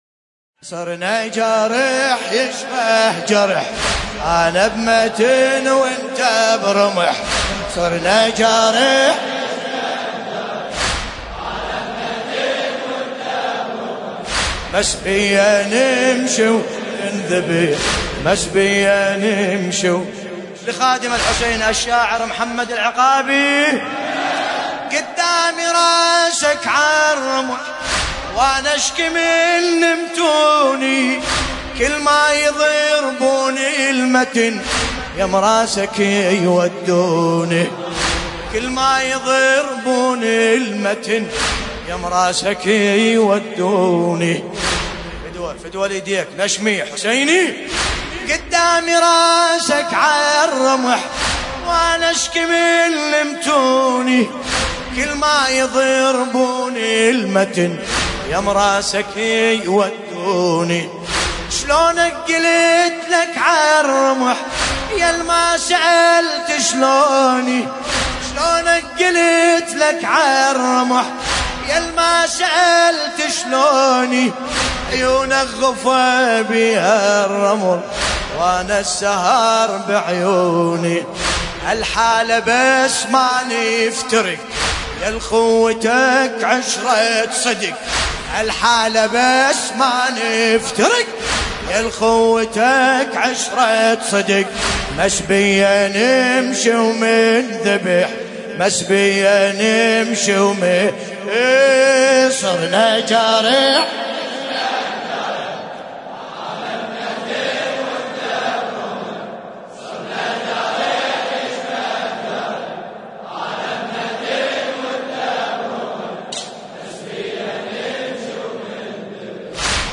الرادود : الحاج ملا باسم الكربلائي